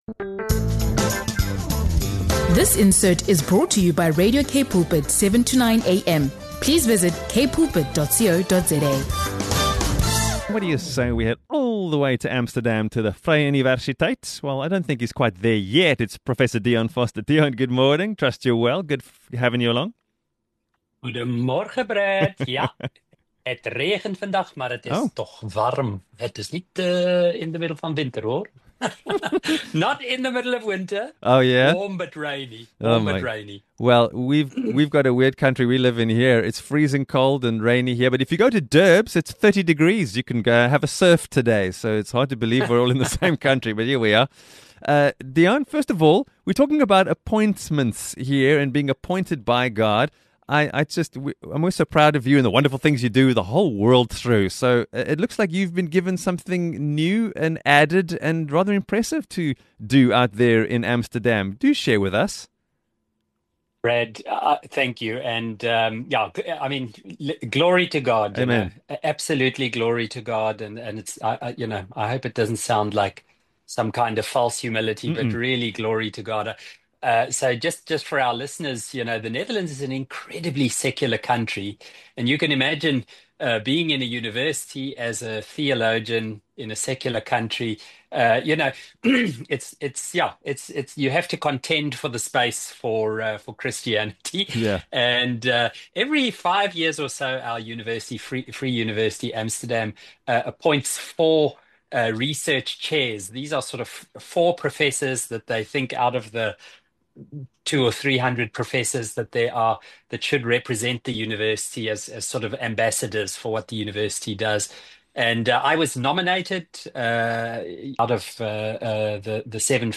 In this uplifting conversation